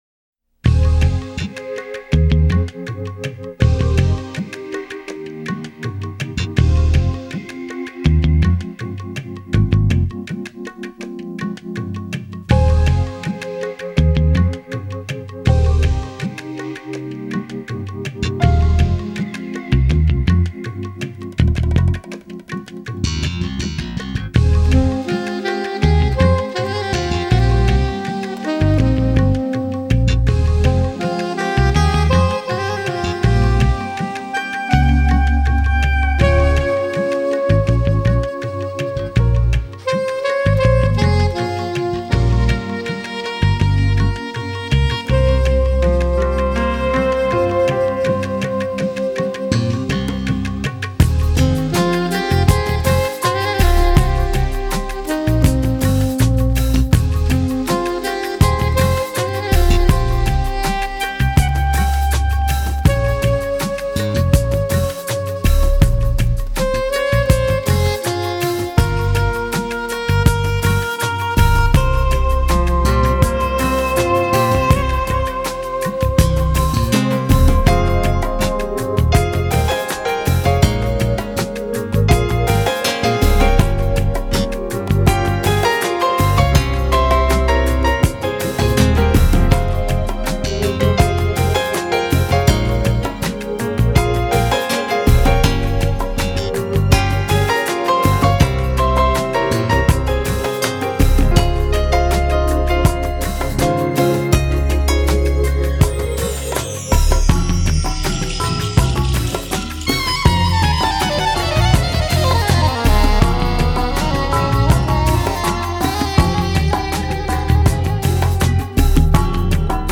Немного фортепиано.